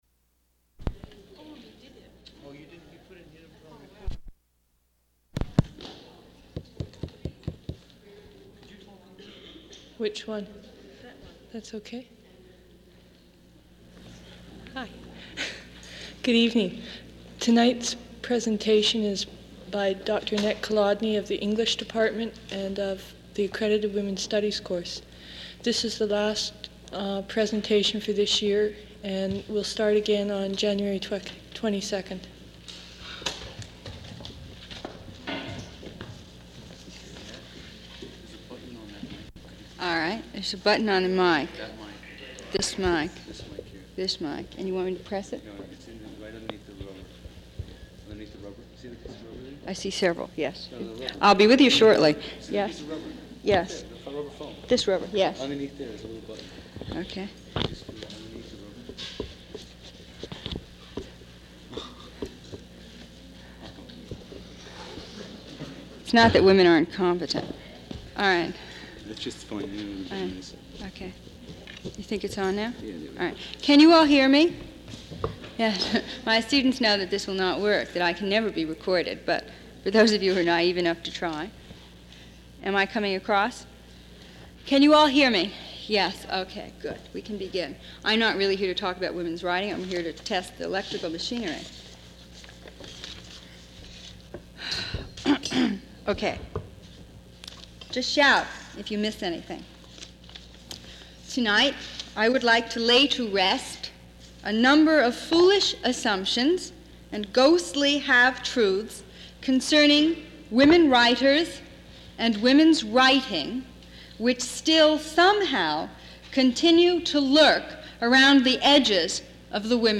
Recording of an address